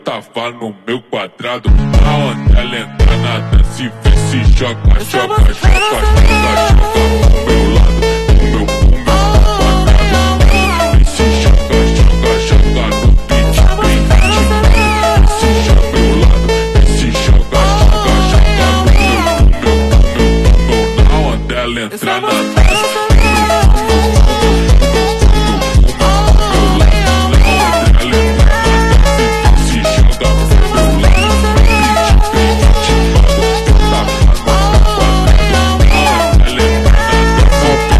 Brazilian phonk